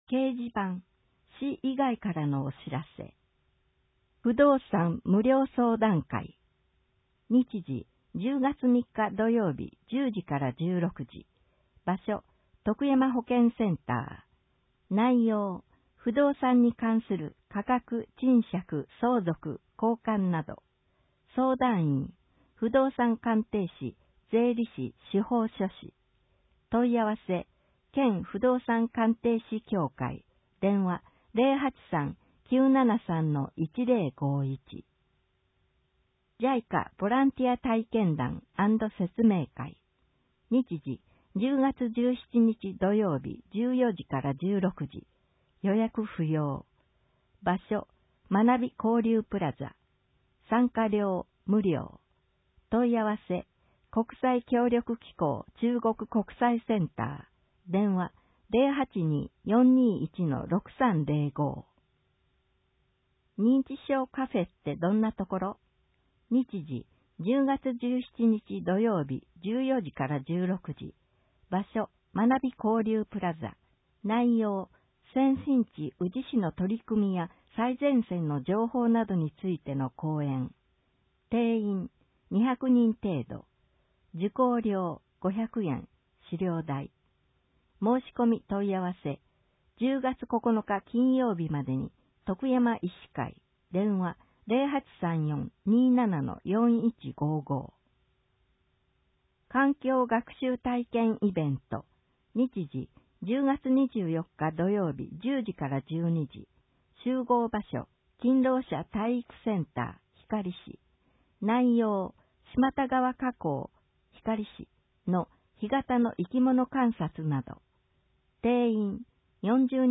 音訳広報
広報しゅうなんを、音読で収録し、mp3形式に変換して配信します。
この試みは、「音訳ボランティアグループともしび」が、視覚障害がある人のために録音している音読テープを、「周南視聴覚障害者図書館」の協力によりデジタル化しています。